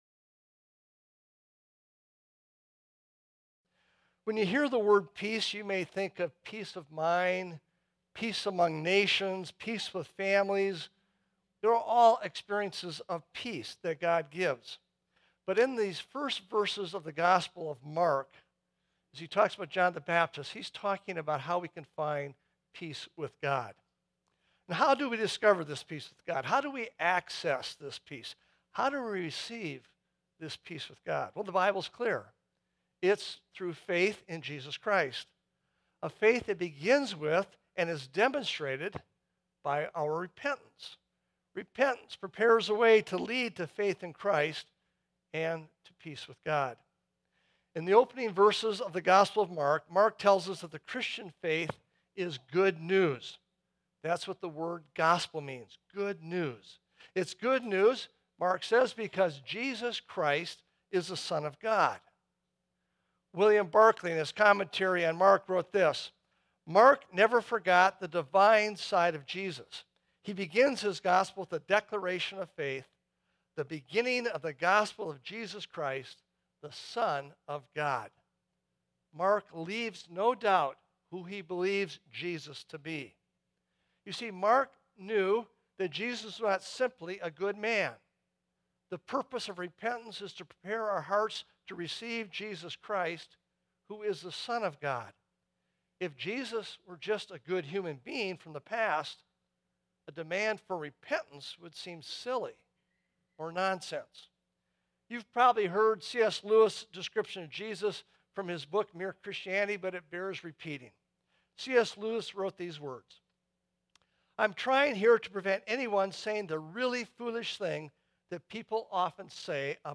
Sermons – Desert Hope Lutheran Church
Download Christ The King Sunday